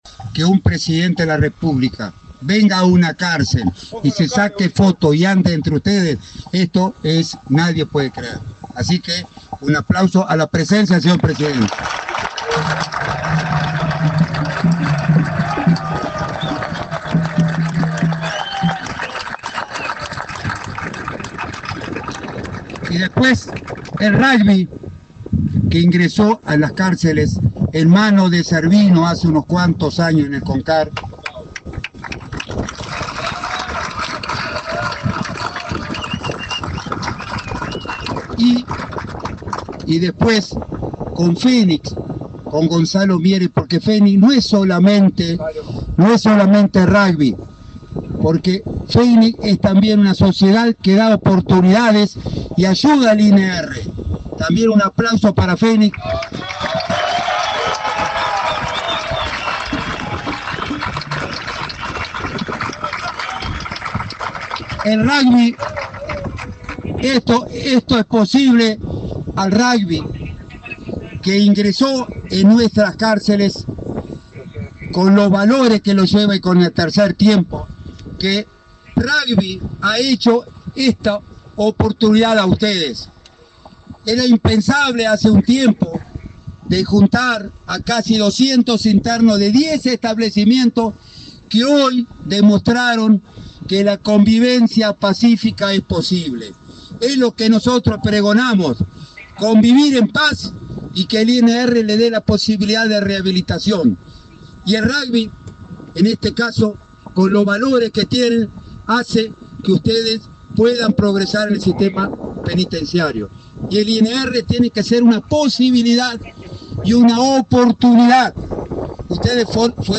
Declaraciones del director del INR, Luis Mendoza
El director del Instituto Nacional de Rehabilitación (INR), Luis Mendoza, participó en el 2.° Encuentro de Rugby Intercarcelario, en la Unidad n.° 1